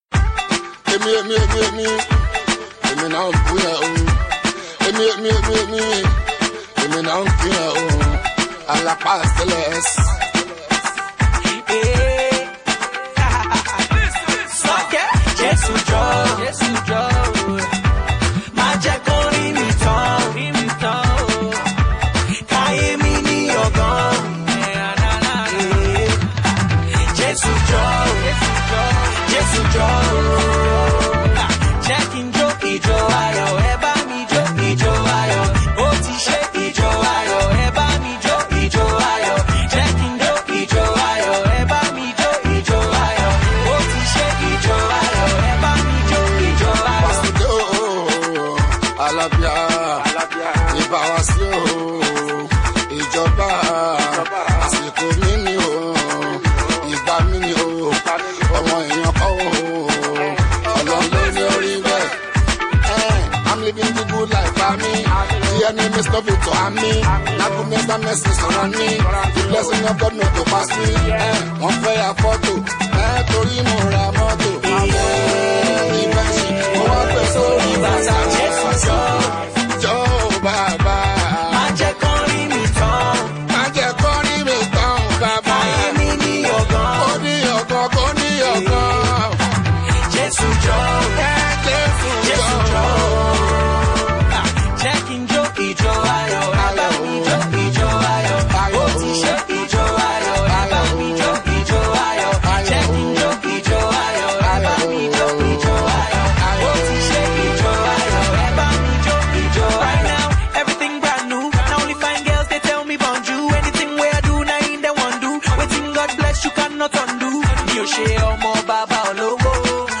Fuji
street-hop/rap